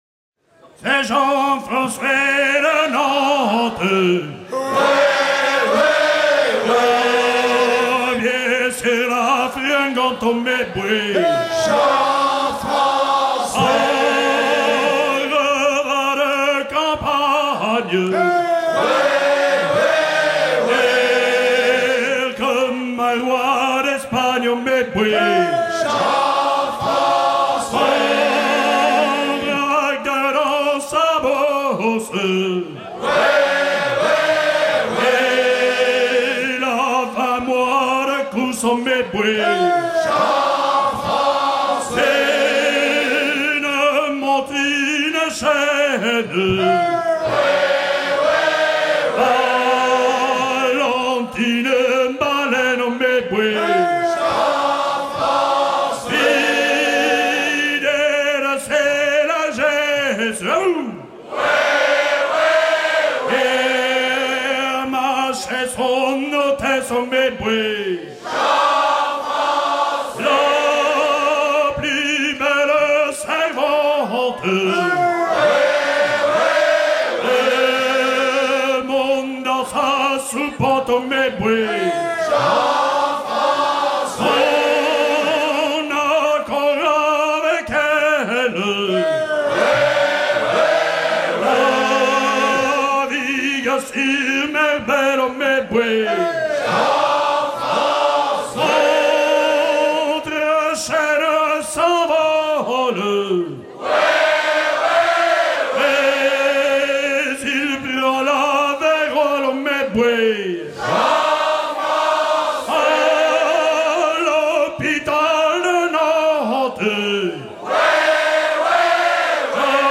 Chant enregistré lors de Vendée 98
Pièce musicale éditée